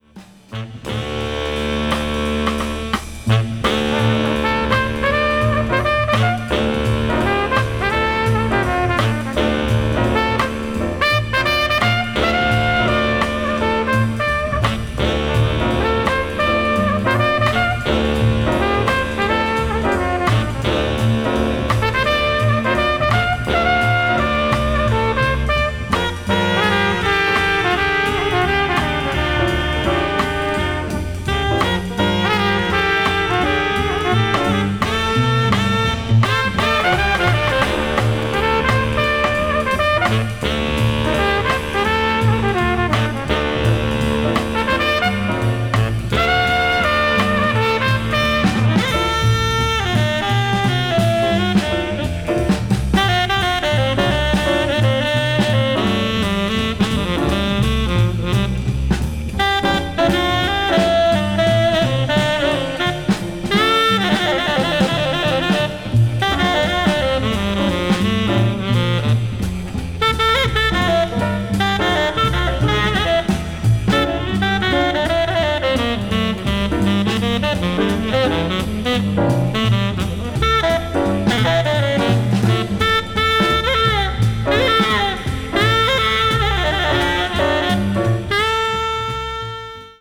media : EX+/EX+(一部わずかにチリノイズが入る箇所あり,盤側面に再生に影響ない小さなカケあり)
こちらはリリース年不明(おそらく2000年代)の再発スペイン盤ですが、メリハリのある良い音質です。